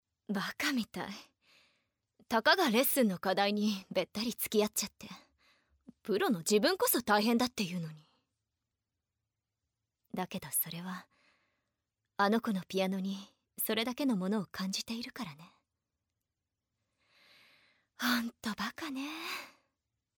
スワロ　エレクトロの声優は誰？（ボイスサンプル付き）
CV：伊藤静
ボイスンプル